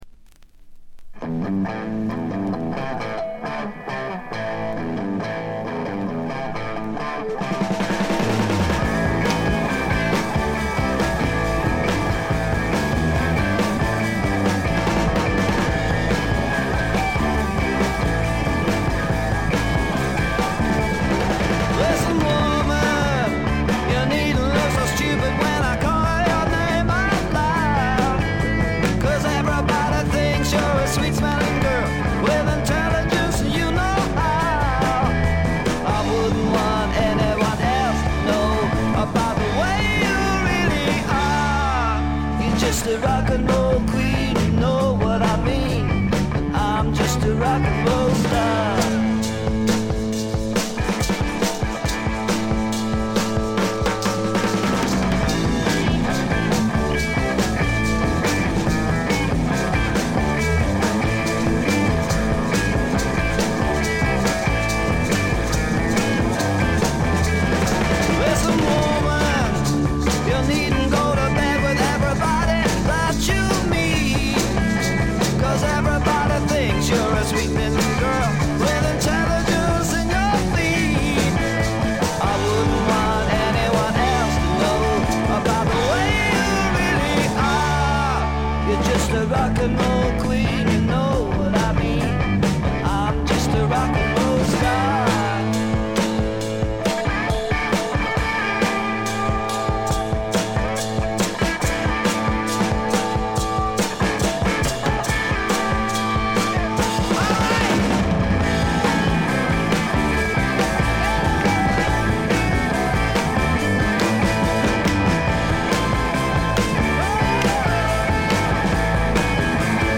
部分試聴ですが、微細なノイズ感のみ、極めて良好に鑑賞できると思います。
試聴曲は現品からの取り込み音源です。